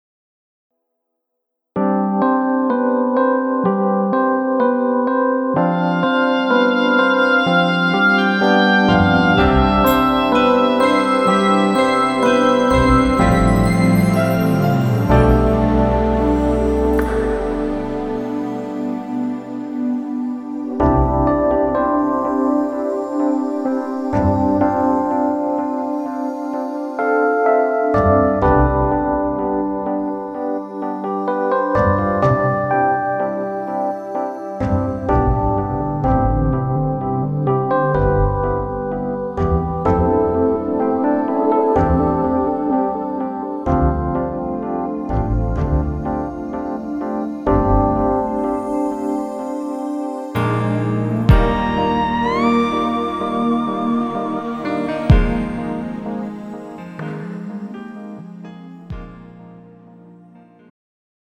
음정 (원키)
장르 축가 구분 Pro MR